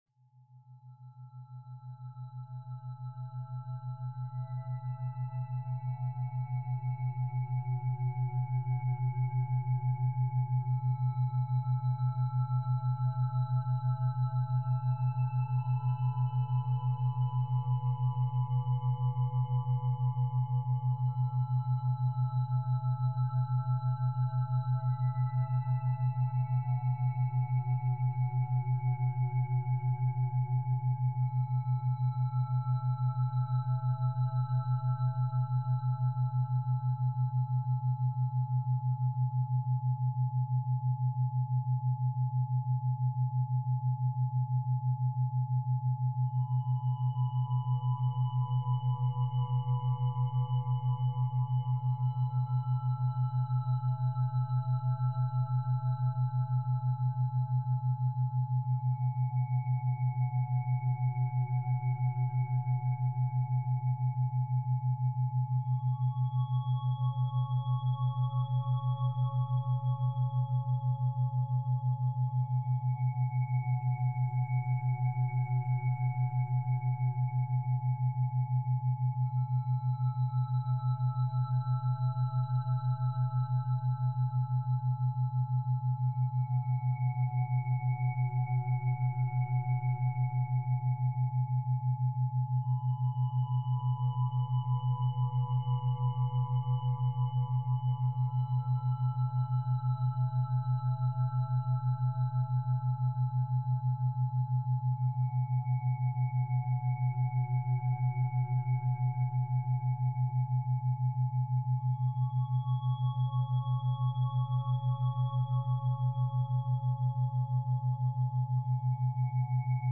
Complete Solfeggio Matrix – 9 Fork Frequencies for Balance